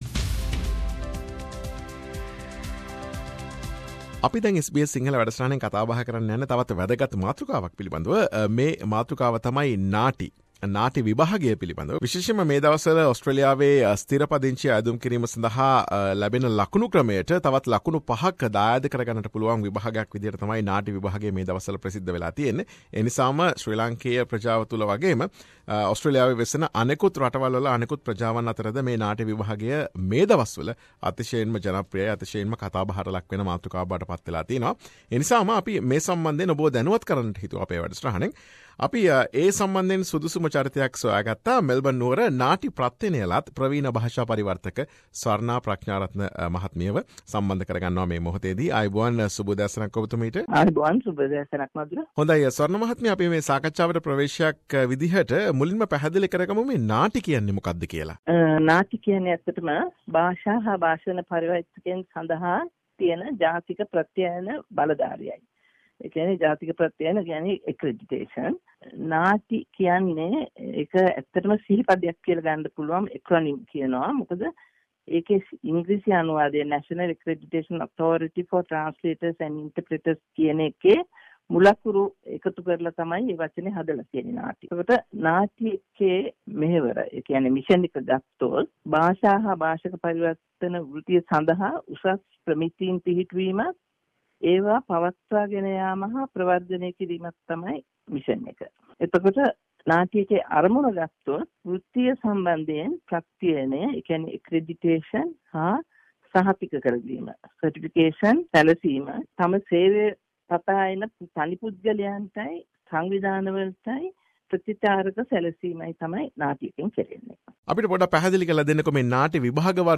SBS සිංහල ගුවන්විදුලිය සිදු කළ සාකච්ඡාව.